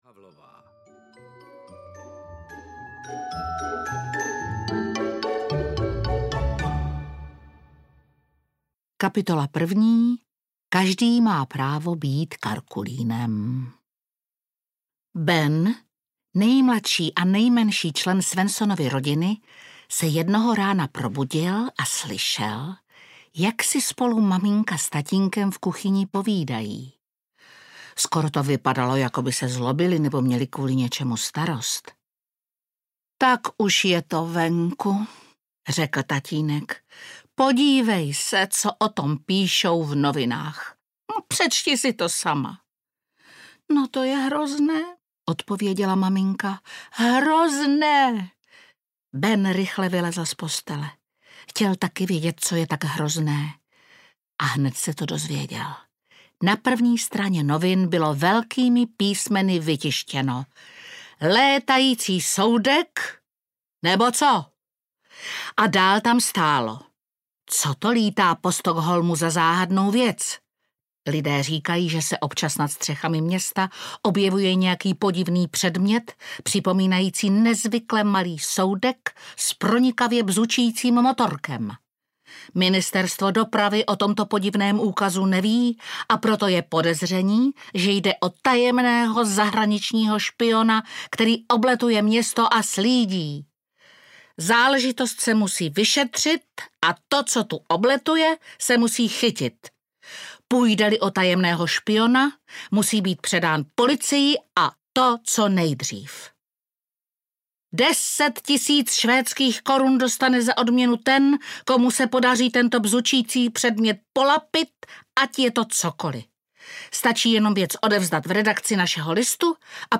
Karkulín je nejlepší audiokniha
Ukázka z knihy
• InterpretDagmar Havlová